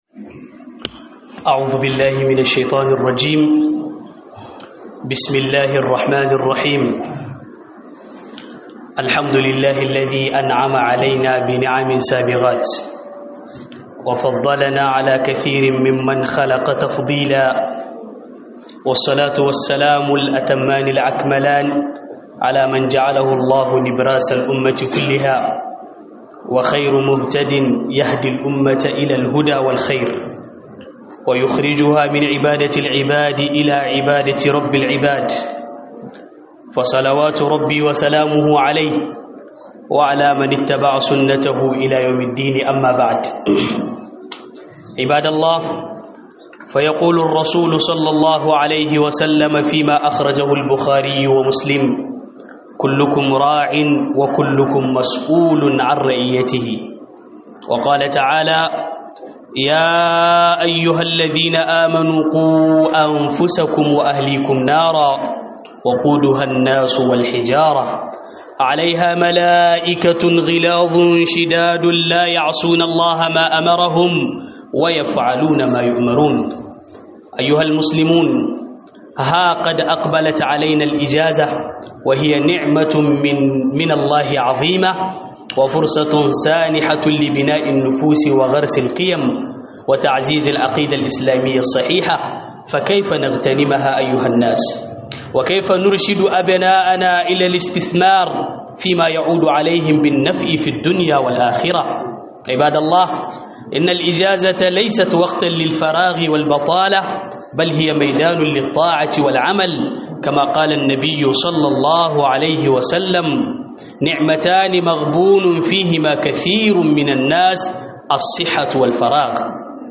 Huduba akan vacance - HUDUBA